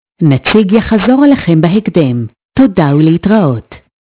he_speech_demo_2.wav